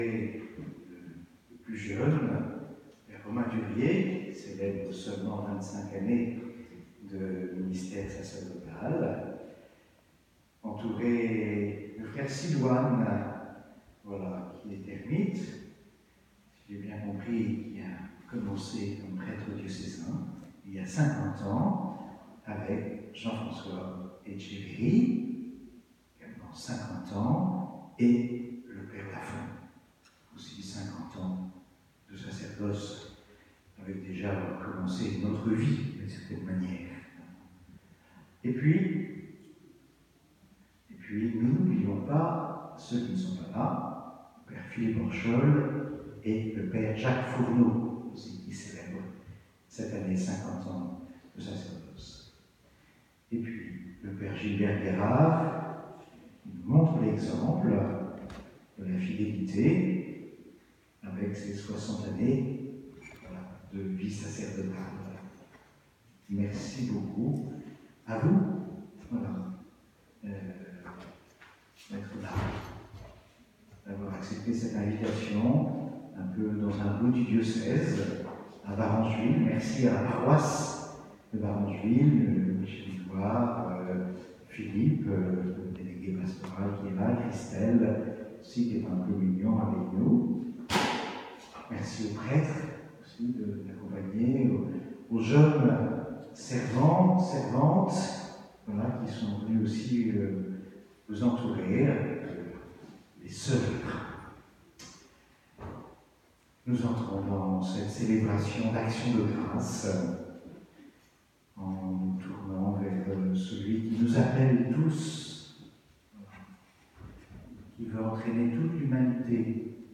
le 1er juillet 2021, à Varengeville sur mer
Célébration de la messe (1 sur 2)
Présentation des jubilaires par Mgr Dominique Lebrun
archevêque de Rouen (2mn)